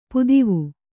♪ pudivu